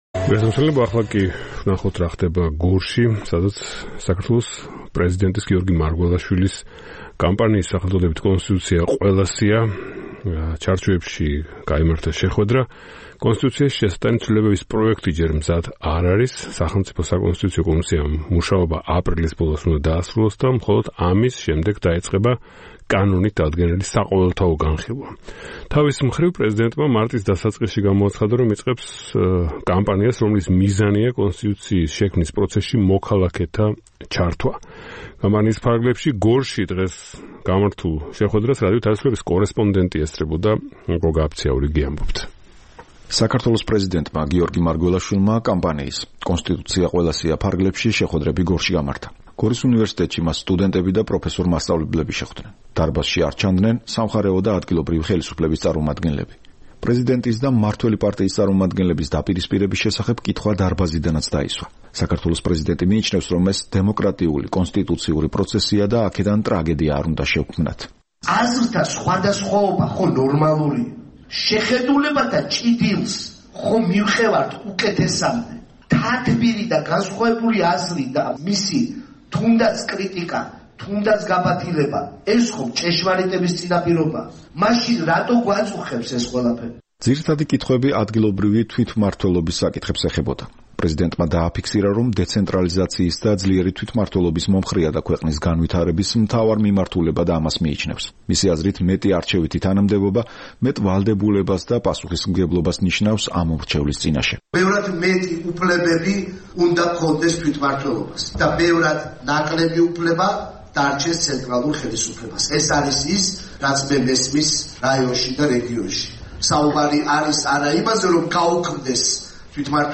საქართველოს პრეზიდენტმა გიორგი მარგველაშვილმა, კამპანიის „კონსტიტუცია ყველასია“ ფარგლებში, გორში შეხვედრები გამართა. გორის უნივერსიტეტში მას სტუდენტები და პროფესორ-მასწავლებლები შეხვდნენ. დარბაზში სამხარეო და ადგილობრივი ხელისუფლების წარმომადგენლები არ ჩანდნენ. პრეზიდენტისა და მმართველი პარტიის წარმომადგენლების დაპირისპირების შესახებ კითხვა დარბაზიდანაც დაისვა. საქართველოს პრეზიდენტი მიიჩნევს, რომ ეს დემოკრატიული კონსტიტუციური პროცესია და აქედან ტრაგედია არ უნდა შევქმნათ.
პრეზიდენტის შეხვედრა გორელებთან